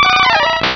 Cri de Colossinge dans Pokémon Rubis et Saphir.